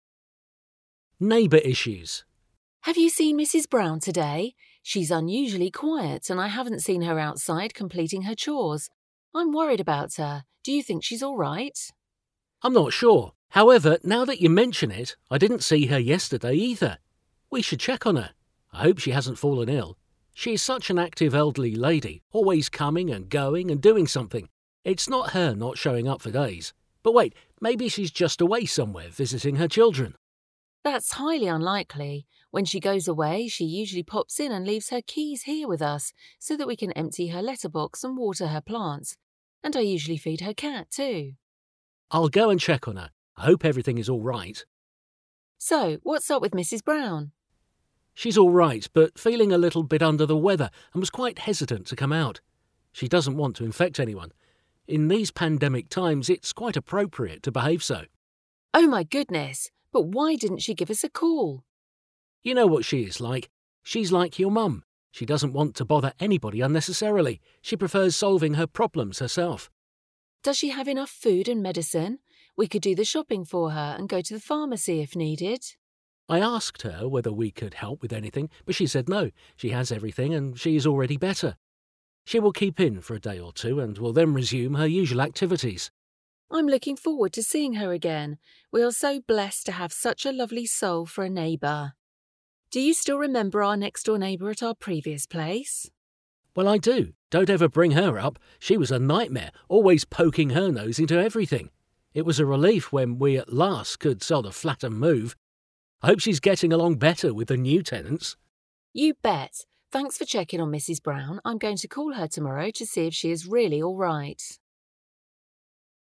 A magazin 80. oldalán található párbeszédet hallgathatod meg itt.